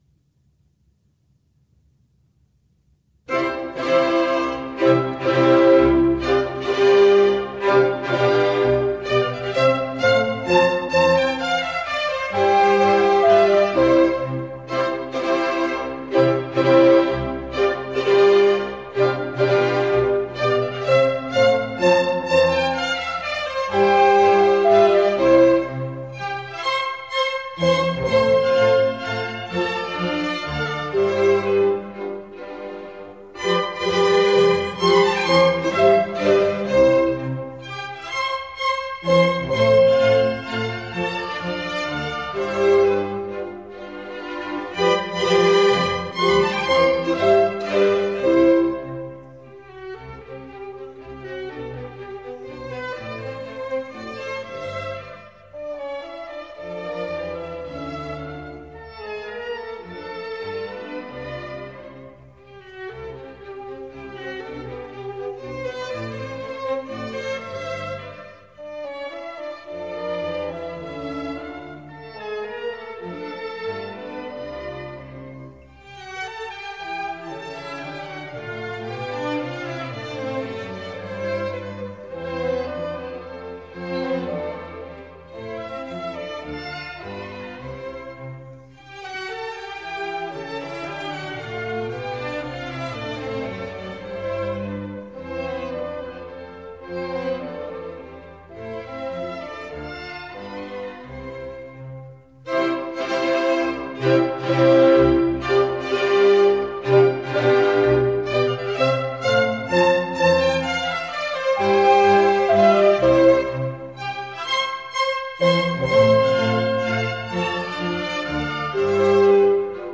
C Major